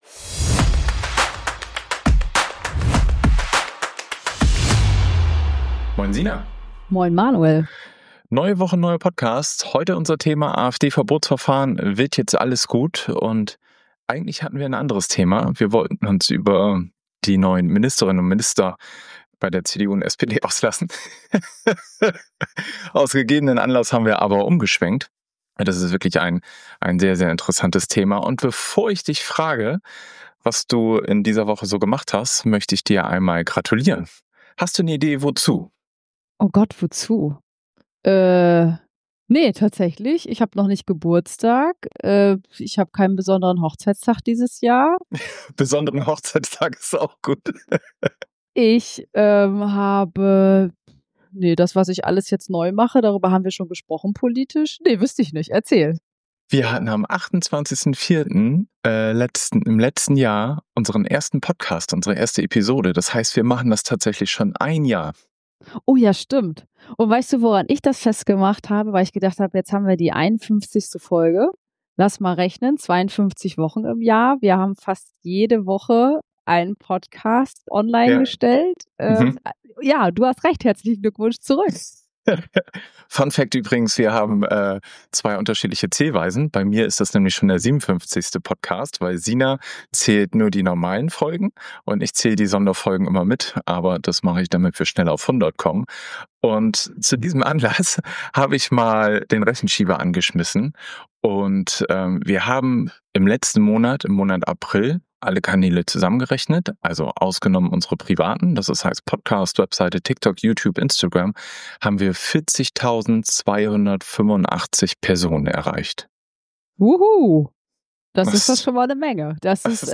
Wir nehmen euch mit in eine intensive Diskussion, die zwischen persönlicher Meinung, fundierter Analyse und einer klaren Haltung oszilliert.